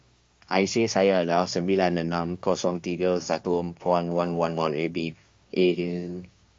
singlish-speaker2050-0.6b.mp3 · mesolitica/Malaysian-TTS-0.6B-v1 at main